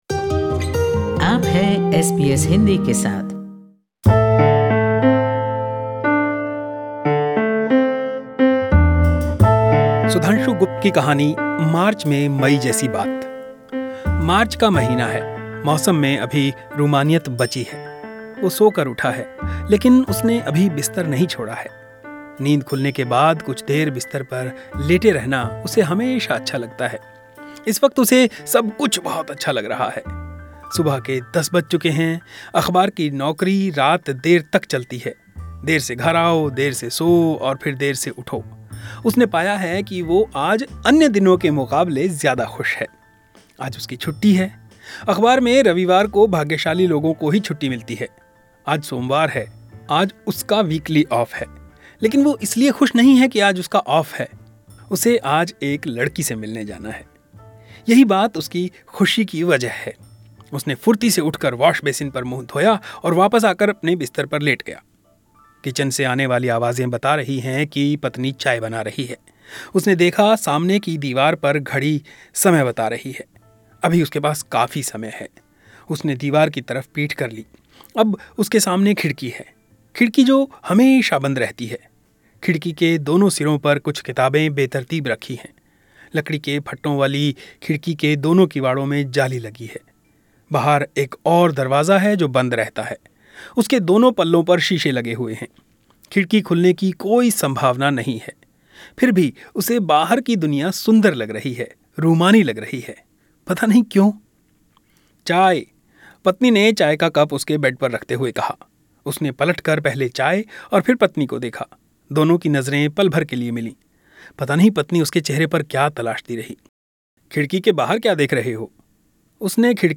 सुनिए उनकी कहानी - मार्च में मई जैसी बात. और फिर इस कहानी और मौजूदा कथा-परिदृश्य पर उनसे बातचीत भी.